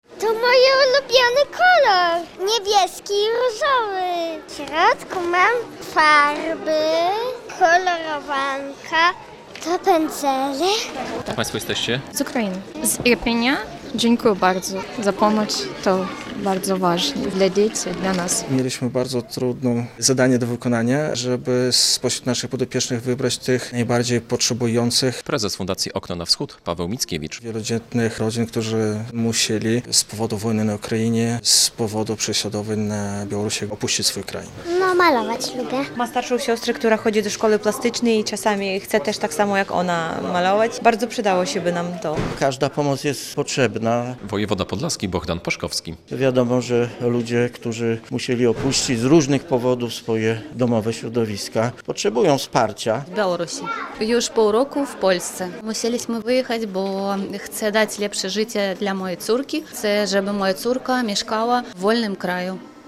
Wyprawki szkolne dla dzieci z Białorusi i Ukrainy - relacja